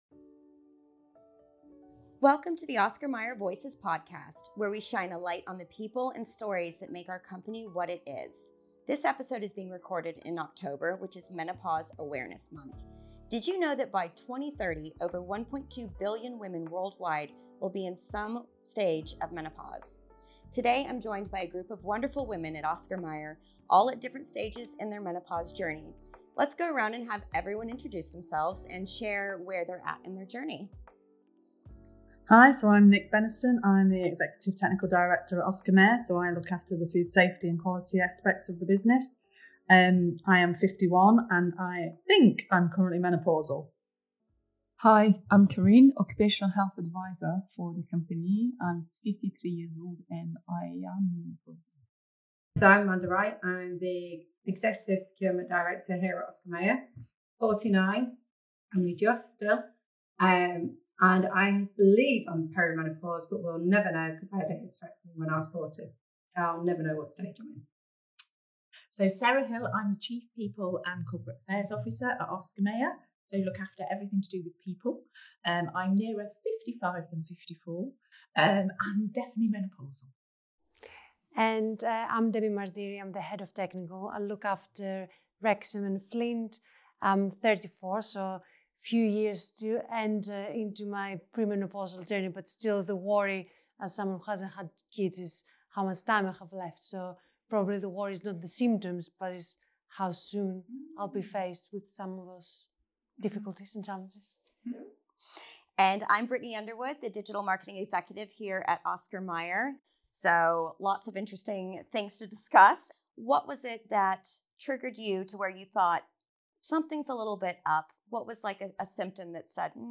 Menopause at Work: Real Talk From Women at Oscar Mayer.
Oscar Mayer Voices is a podcast platform for real conversations from inside our business.